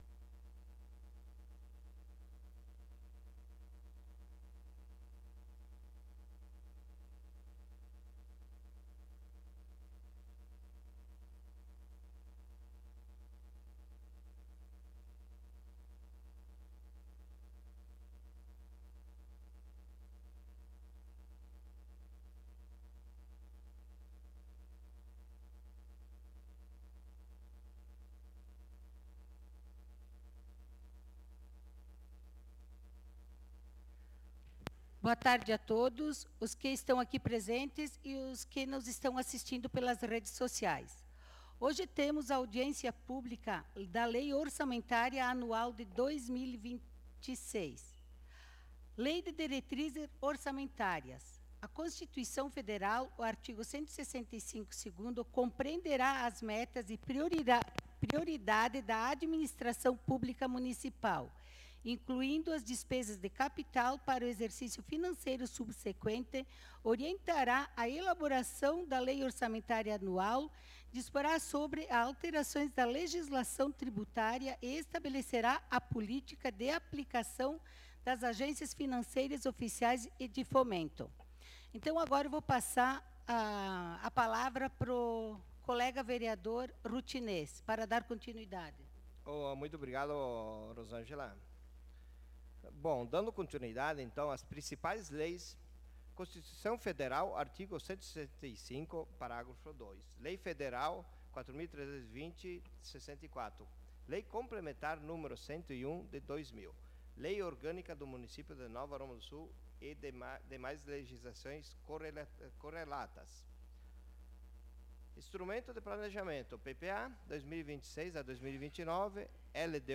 Audiencia_publica_LOA_2....mp3